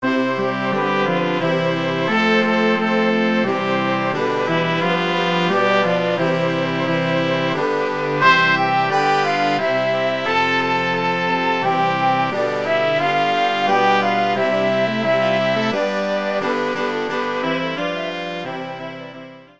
kościelna